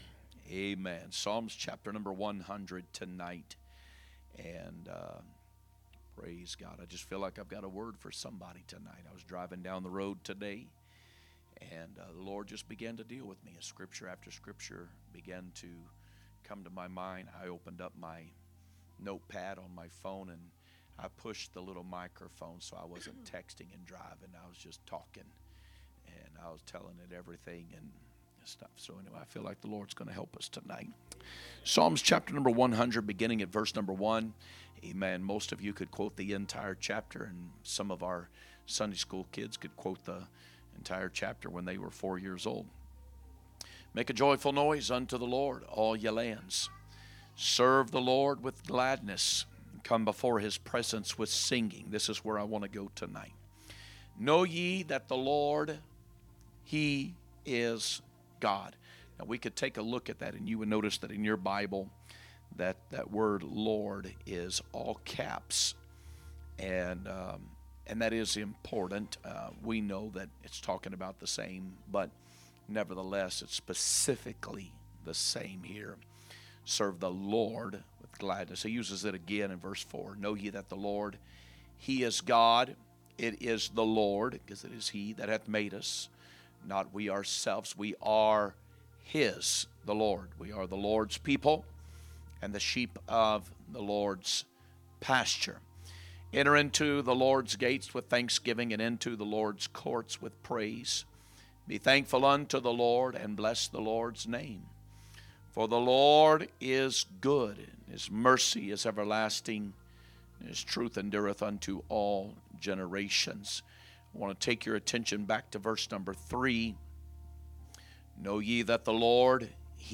Wednesday Message